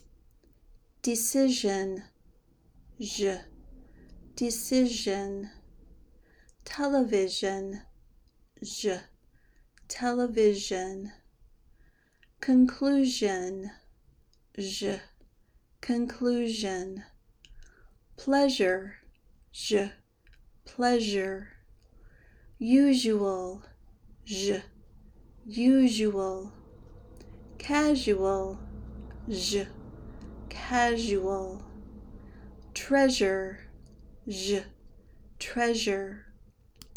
Pronounce SH and ZH in American English
Practice these words with ZH
zh-words.mp3